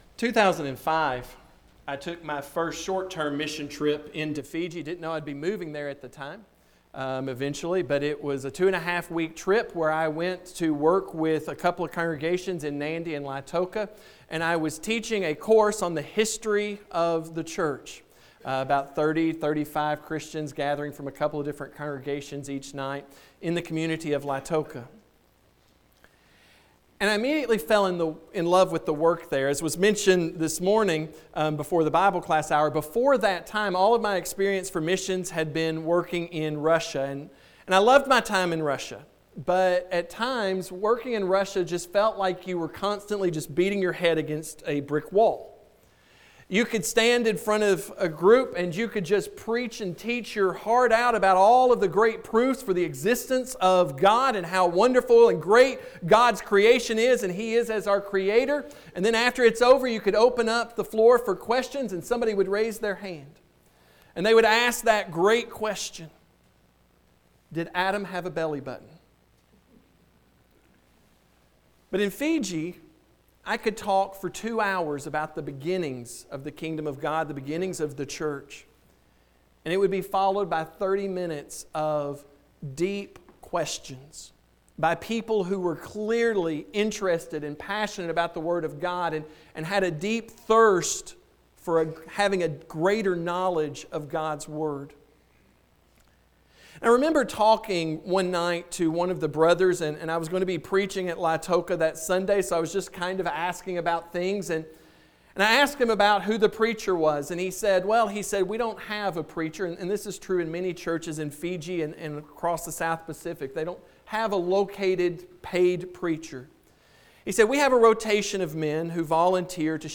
Sermons, Waverly Church of Christ, Page 3